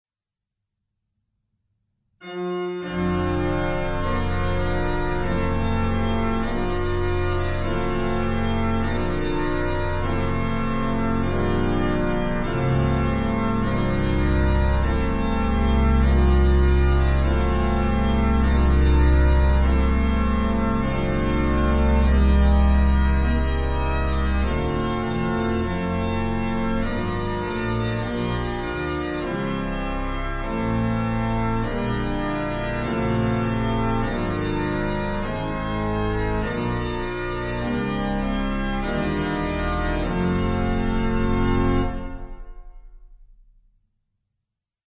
hymn harmonizations